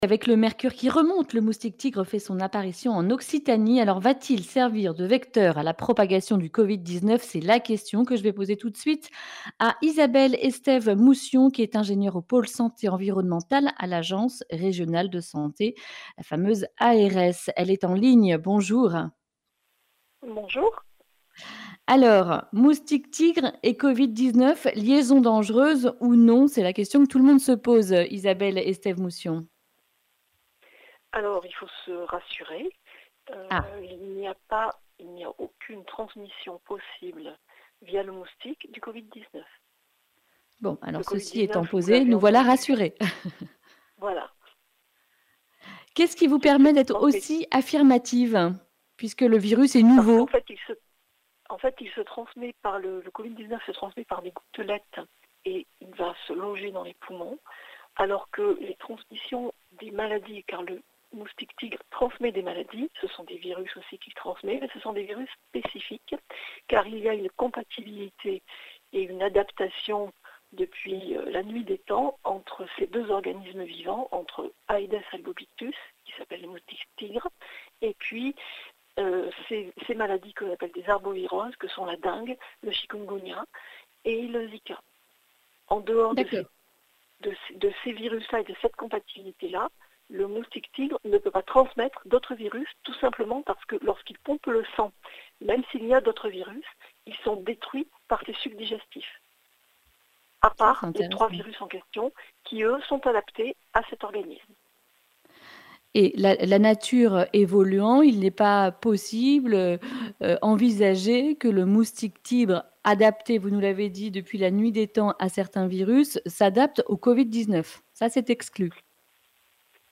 lundi 4 mai 2020 Le grand entretien Durée 10 min